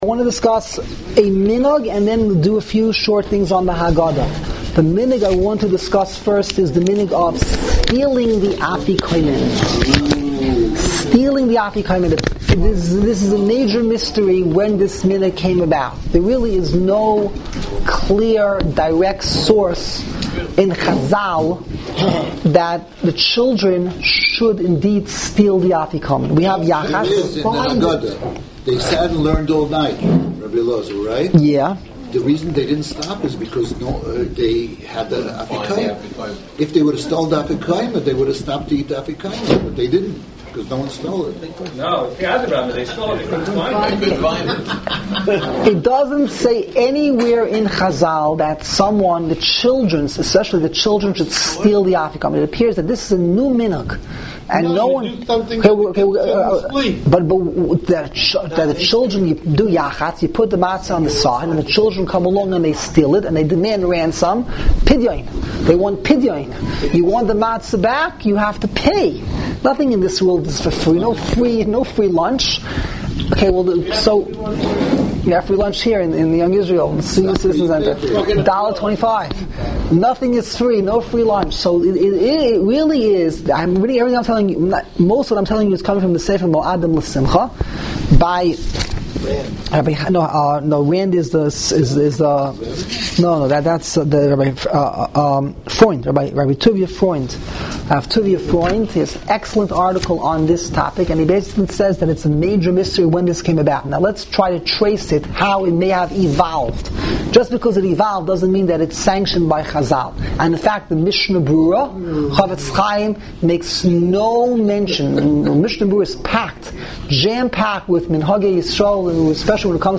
Live Daily Shiurim